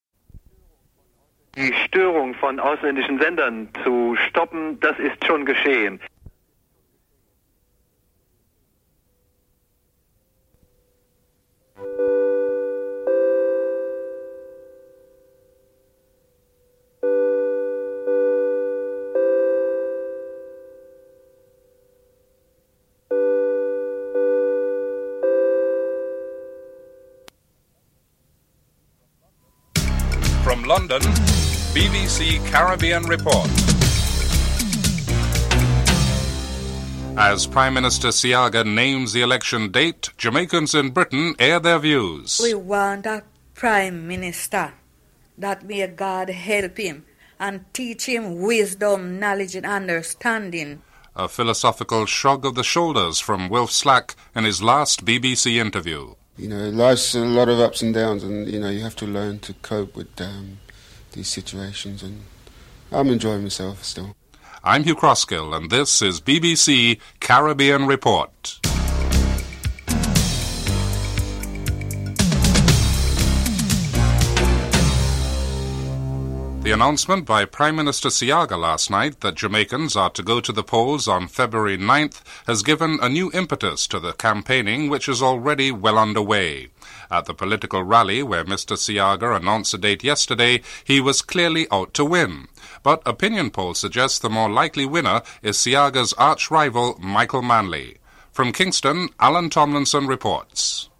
1. Headlines (00:00-01:15)
3. Interviews with persons of Jamaican connection in Britain on the Jamaican elections (05:49-08:45)